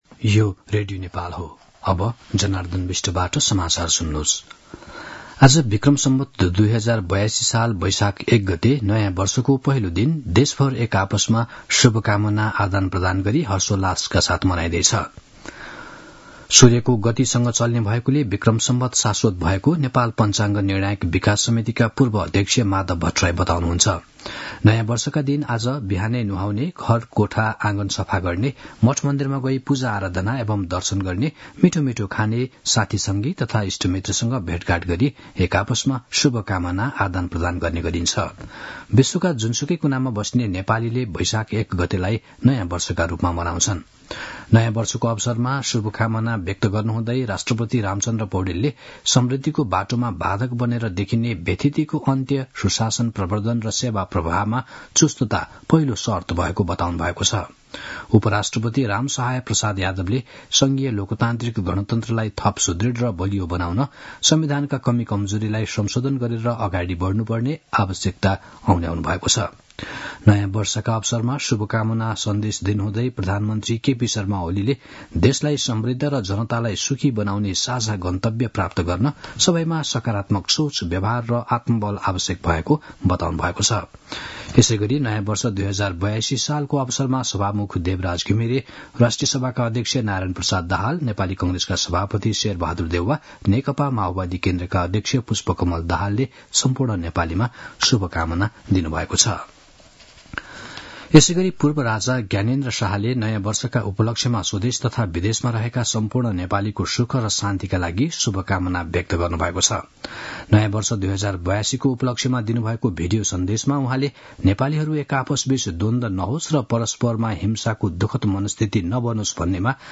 दिउँसो १ बजेको नेपाली समाचार : १ वैशाख , २०८२